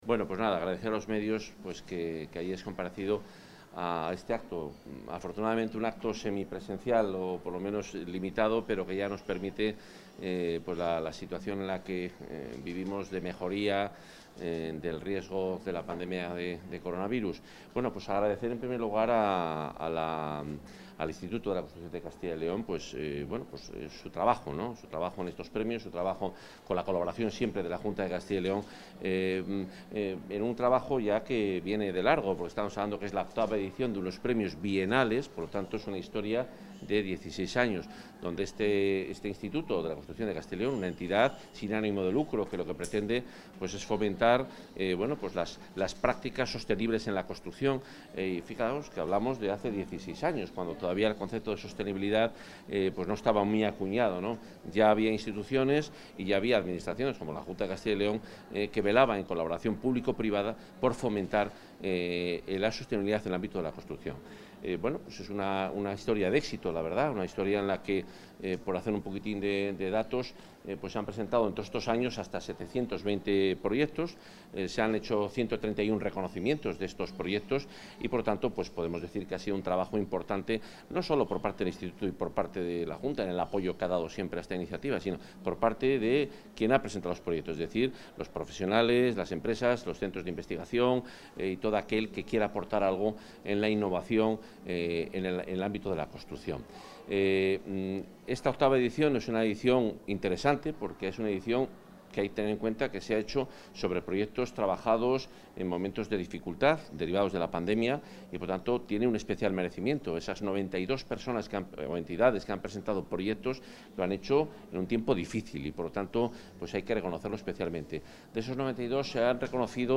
Valoración del consejero de Fomento y Medio Ambiente.
El consejero de Fomento y Medio Ambiente, Juan Carlos Suárez-Quiñones, ha presidido hoy el acto de entrega de la octava edición de los Premios Construcción Sostenible de Castilla y León, convocados por la Junta, con la colaboración del Instituto de la Construcción, donde se han entregado dieciocho galardones (entre premios, áccesits y menciones de honor) en las diferentes categorías (entre las 92 inscripciones presentadas).